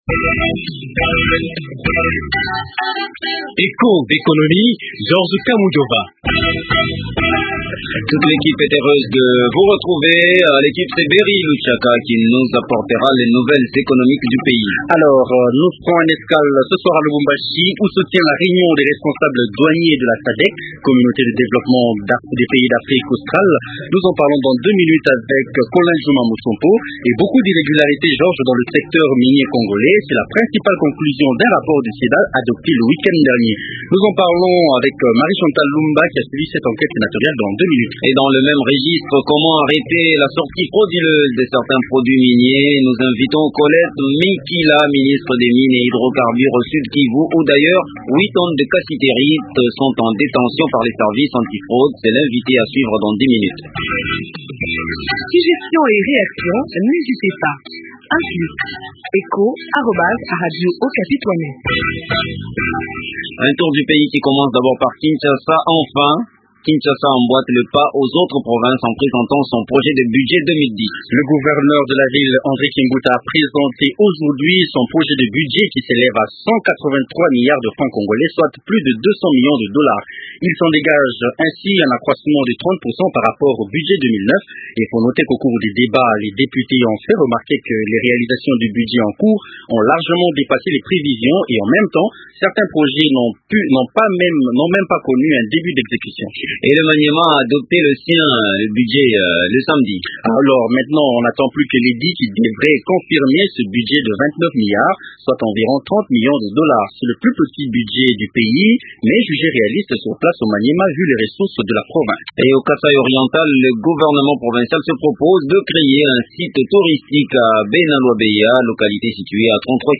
Collette Mikila, ministre des mines et hydrocarbures au Sud Kivu ou d’ailleurs 8 tonnes de cassitérite sont en détention par les services antifraude est l’invité de l’éco magazine.